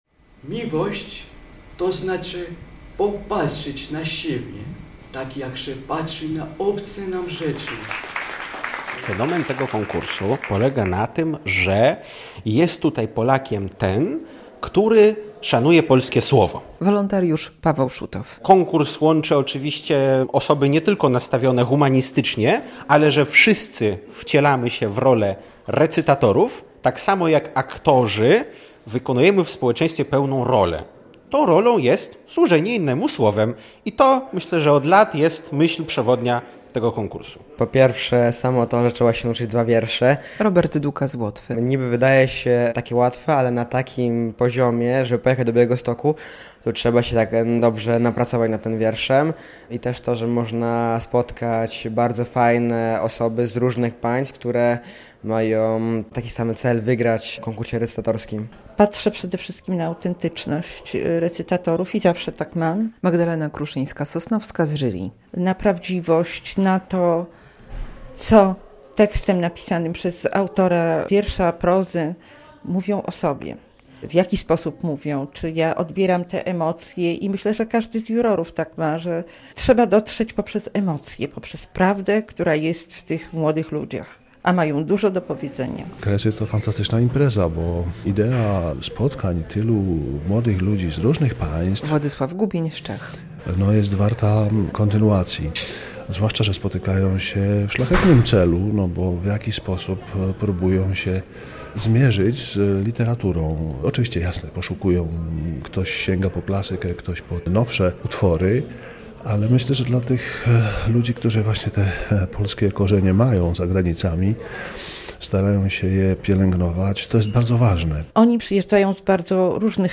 Finaliści przed jury recytują przygotowane fragmenty poezji
W Białostockim Teatrze Lalek od 10:45 finaliści recytują wiersze.